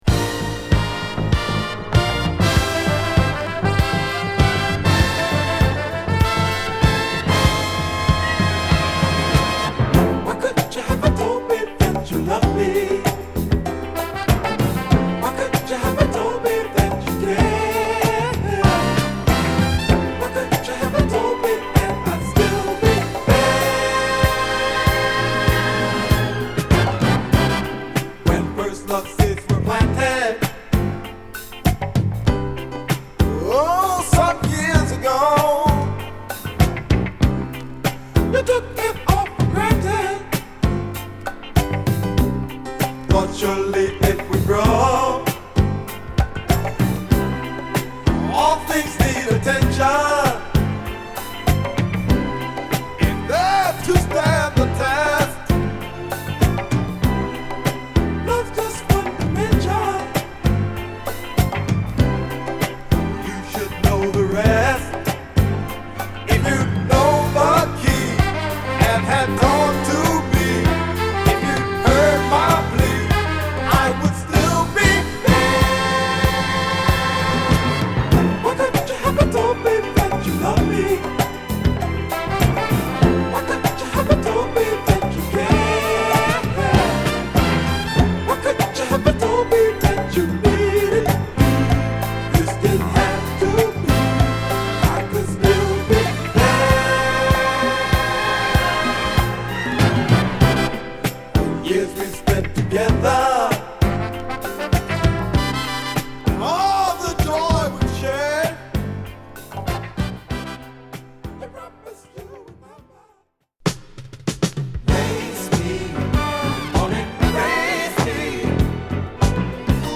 期待を裏切らないメロウ／アーバンソウルからファンキーなディスコまでを披露！